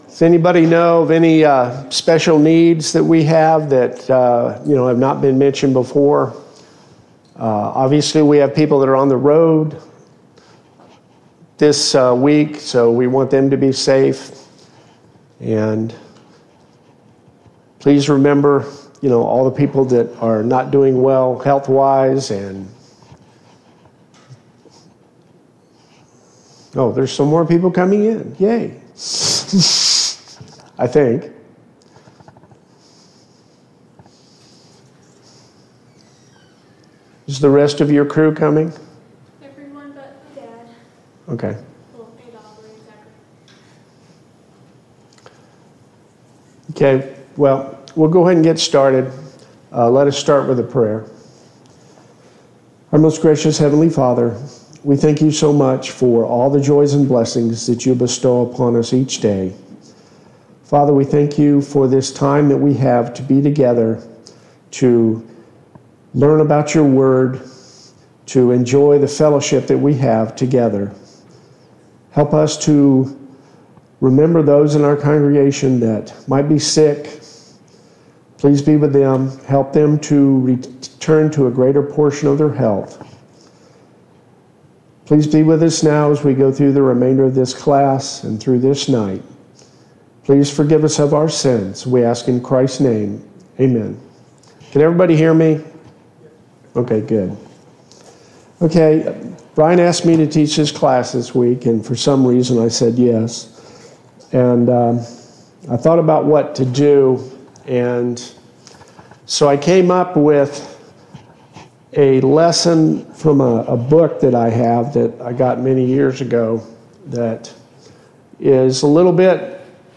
Service Type: Mid-Week Bible Study Topics: Inspiration of the Bible , The Bible « 3.